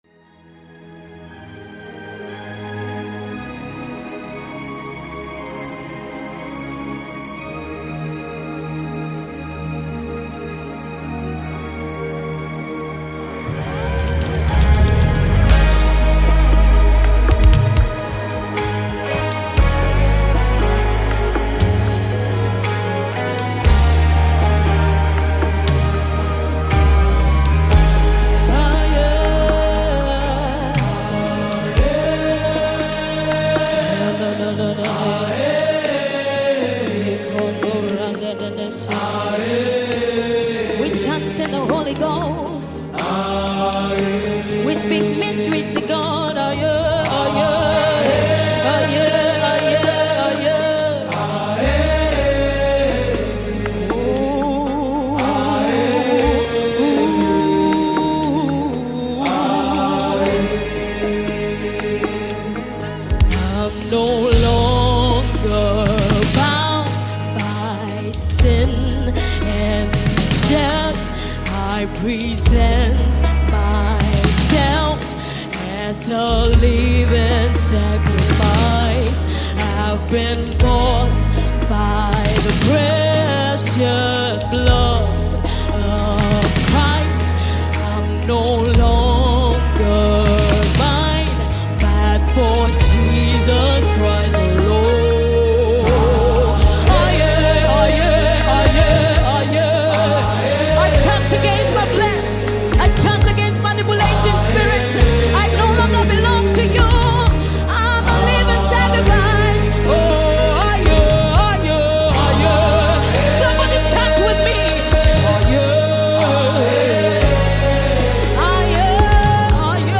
This is a New Single by Ghanaian Gospel Music Minister